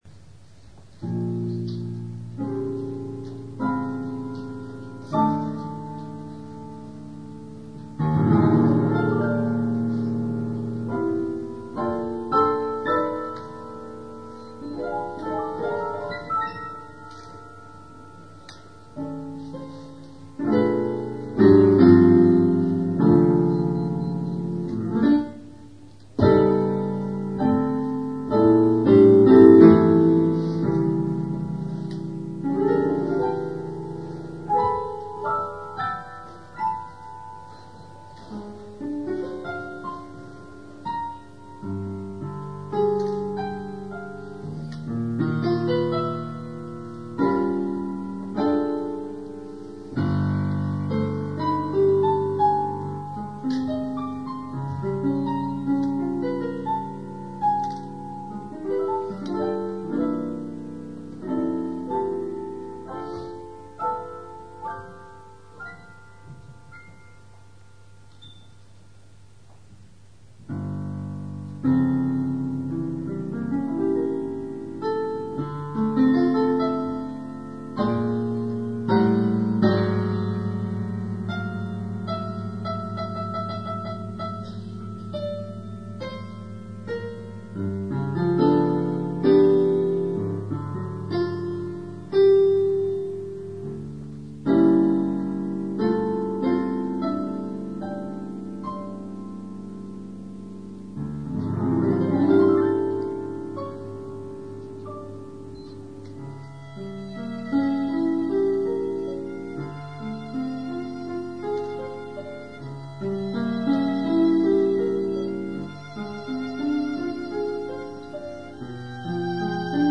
記念シンポジウム「響宴・牧野信一生誕百年」（早稲田大学小野記念講堂）の
を作曲者の御厚意により、当日の録音データにより、再演いたします。
ファイルは大きいですが、高音質です。